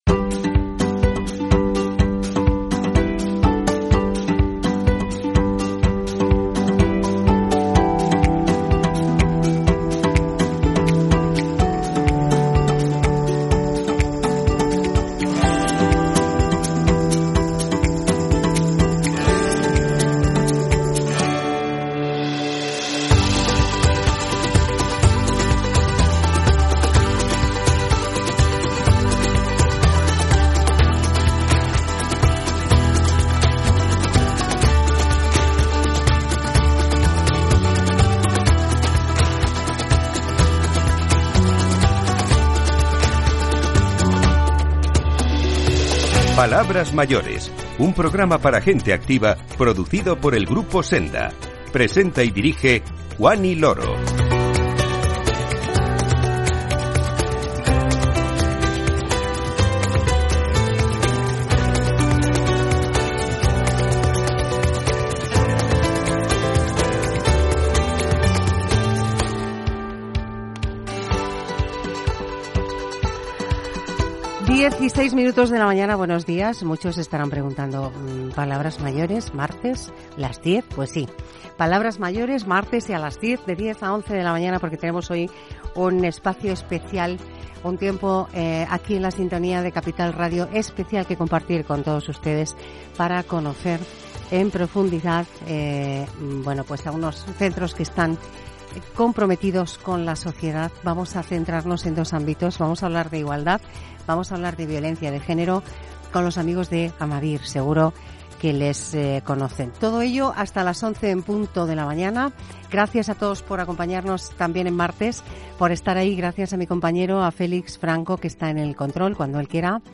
Responsables de Amavir nos visitan hoy para explicar las políticas de la compañía en materia de igualdad y contra la violencia de género.
La iniciativa se presentó en un programa especial de Palabras Mayores en el que representantes de la empresa expusieron las principales líneas de actuación para avanzar hacia entornos laborales más seguros, justos e inclusivos.